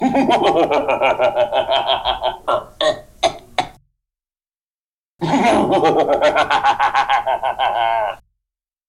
Category 🗣 Voices
Evil Laugh Voice sound effect free sound royalty free Voices